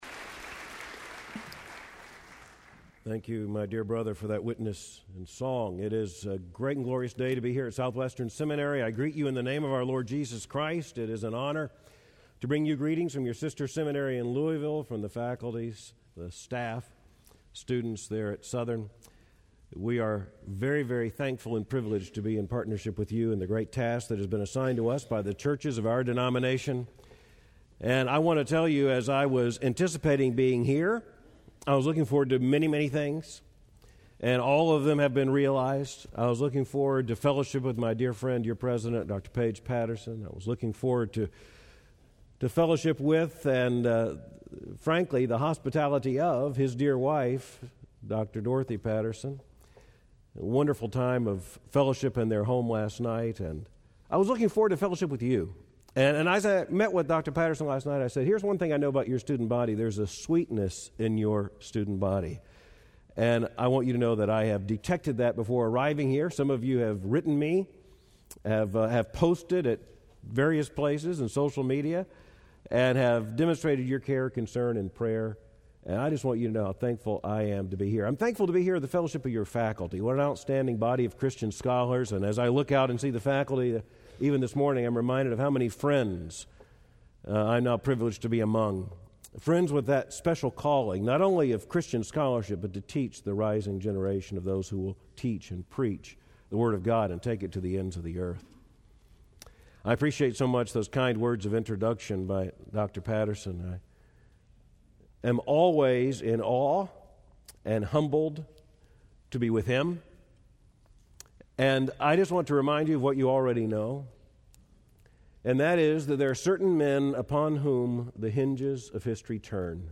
Dr. Al Mohler speaking on Romans 10:1-17 in SWBTS Chapel on Thursday September 10, 2009